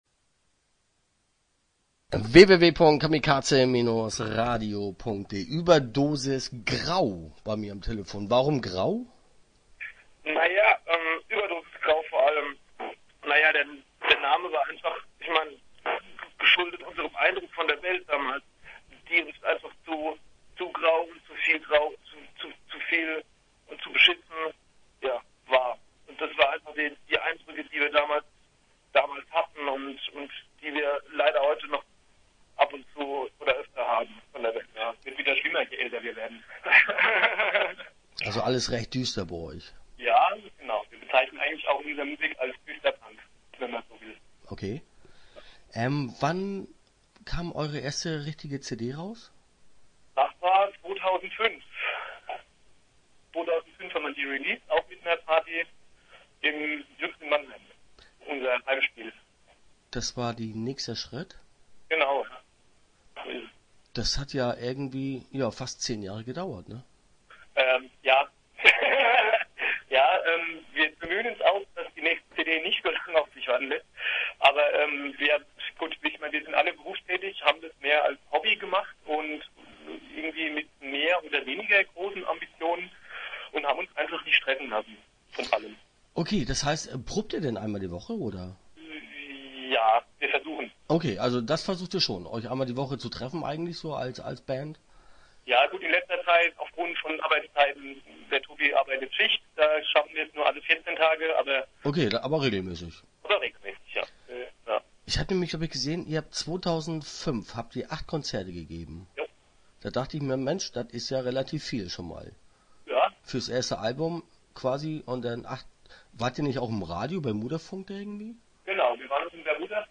Start » Interviews » ueberdosis grau